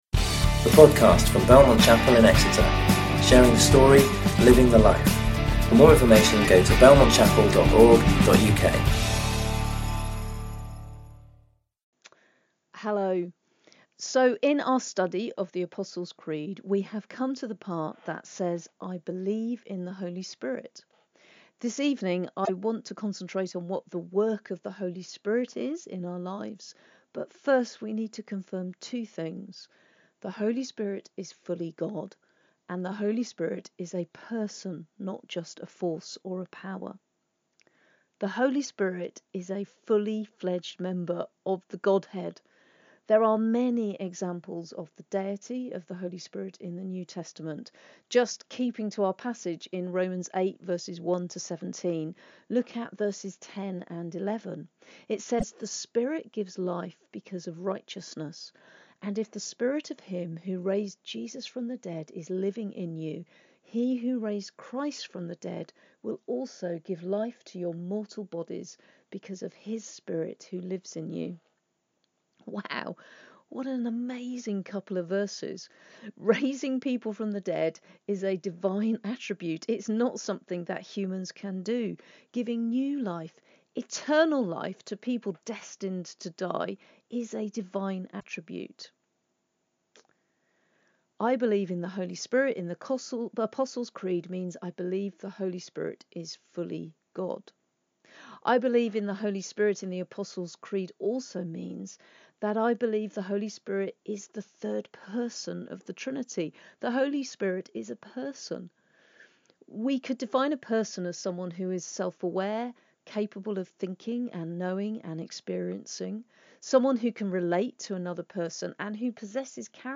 You can listen to or download sermons from Belmont Chapel.